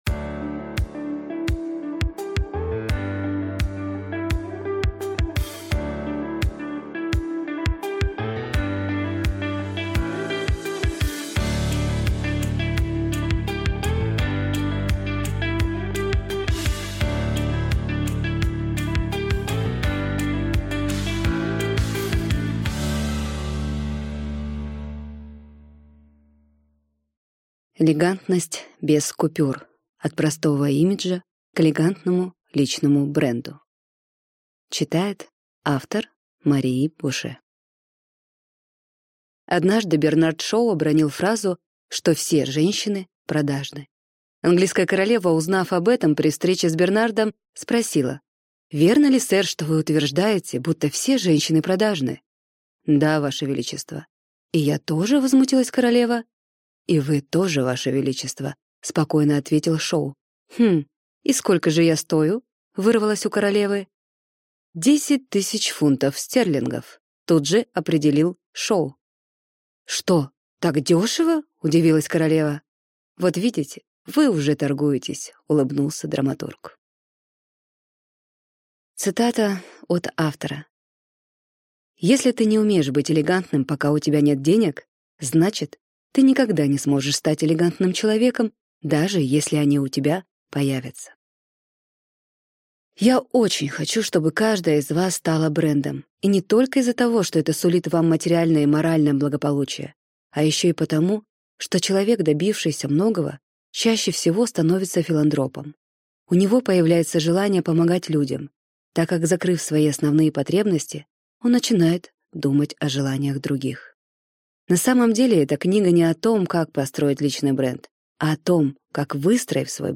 Аудиокнига Элегантность без купюр. От просто имиджа к элегантному личному бренду | Библиотека аудиокниг